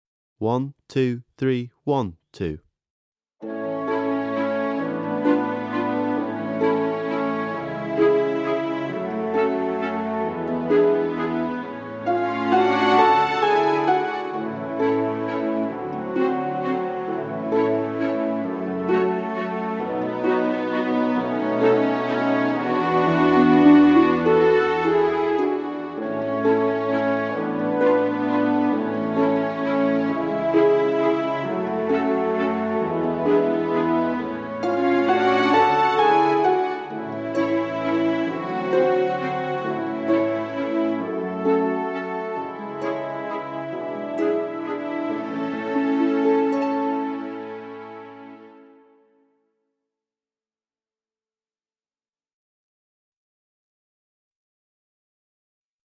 VS All Silk and Satin (backing track)